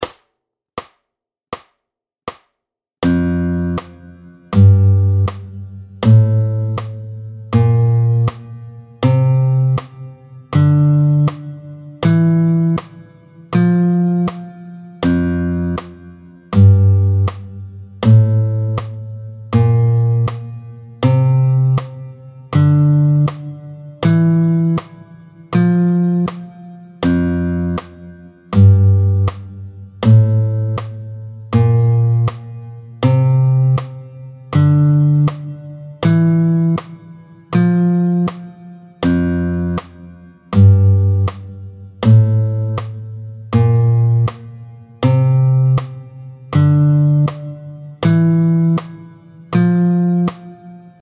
Luth
09-04 La gamme de Fa majeur, tempo 80